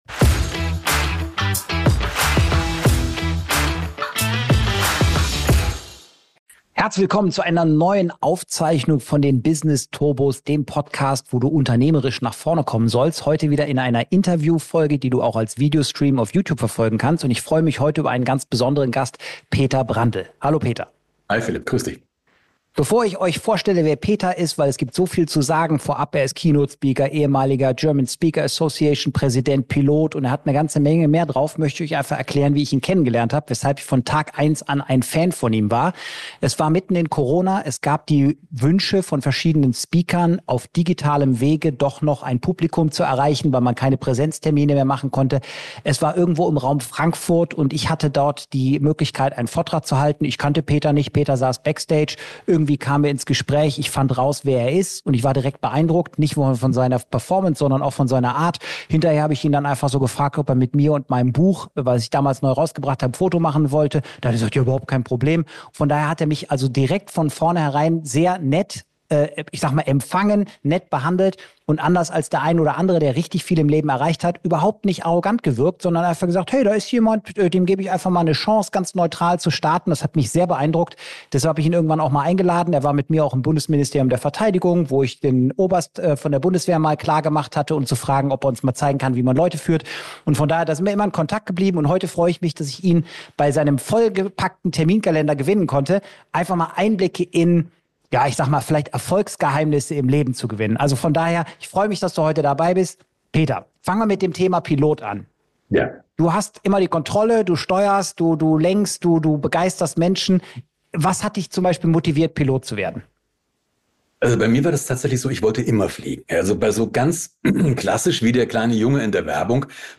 #067 - INTERVIEW